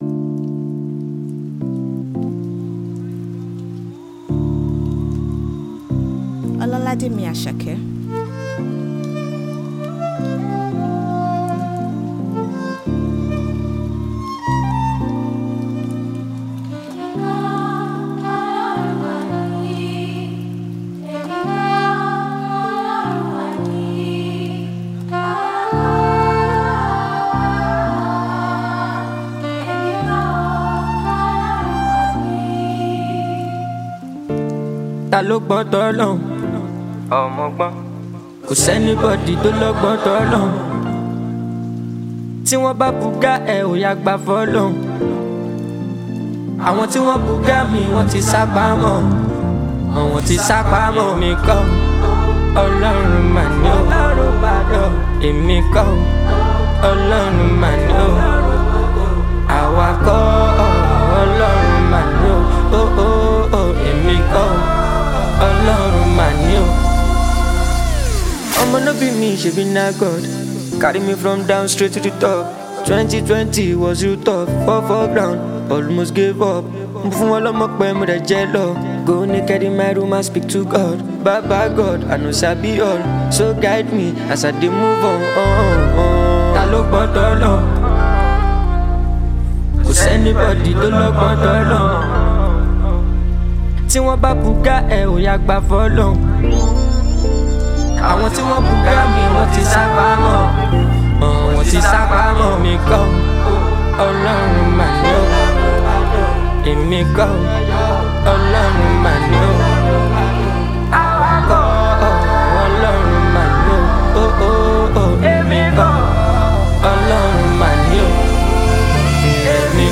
a fusion of Amapiano